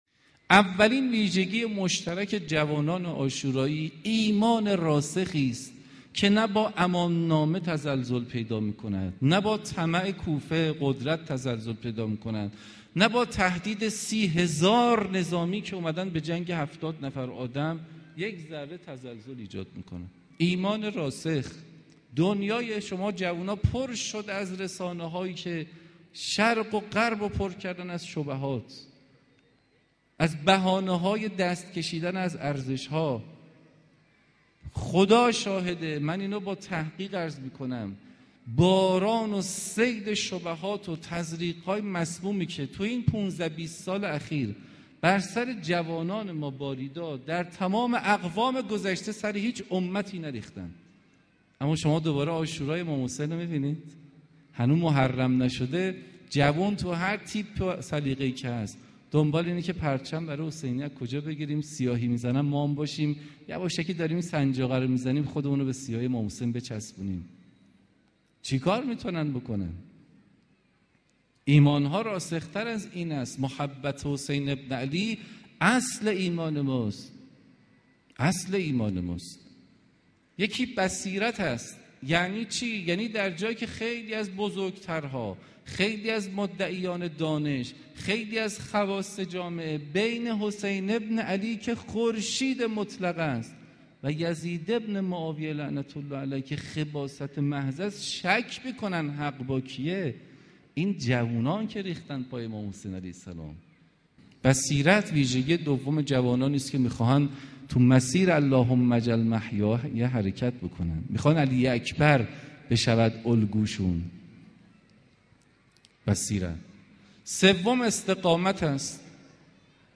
فرازی از سخنان